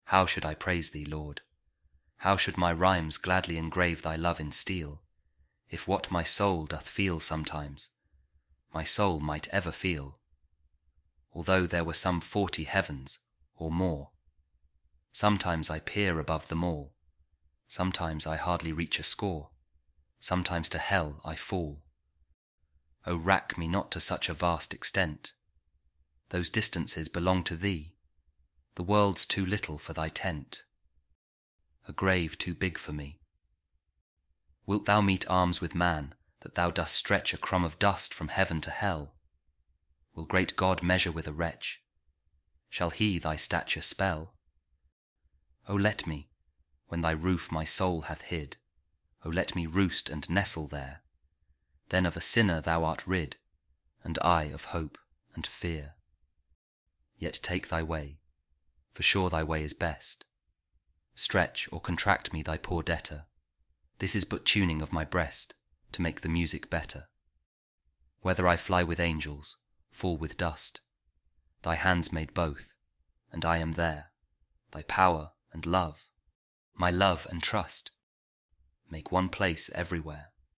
The Temple | George Herbert | The Temper | Audio Poem
george-herbert-temple-temper-audio-poem-1.mp3